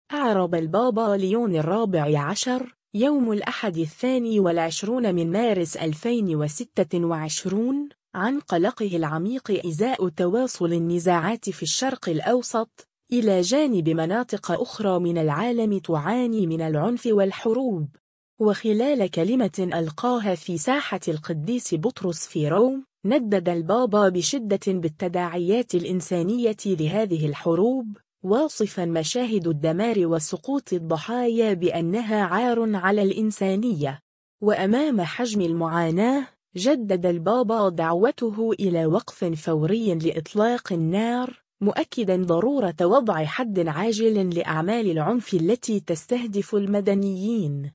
وخلال كلمة ألقاها في ساحة القديس بطرس في روما، ندّد البابا بشدة بالتداعيات الإنسانية لهذه الحروب، واصفًا مشاهد الدمار وسقوط الضحايا بأنها “عار على الإنسانية”.